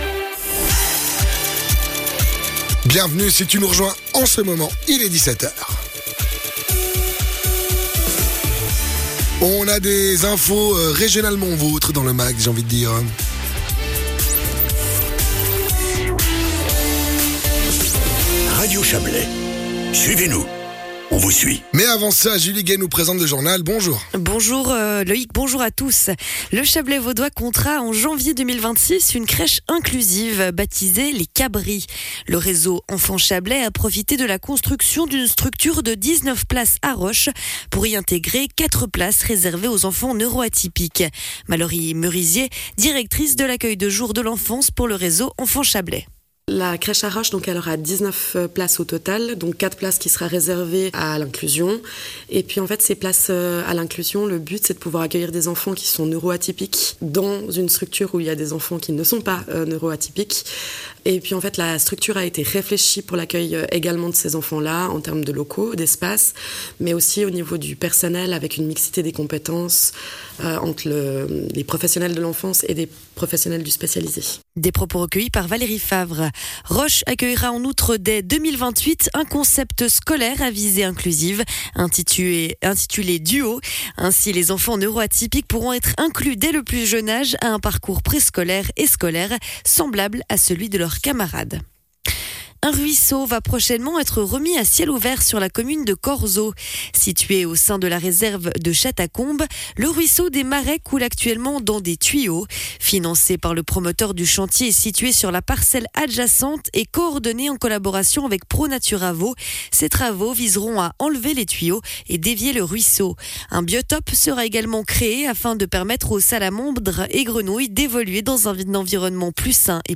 Les infos Radio Chablais en replay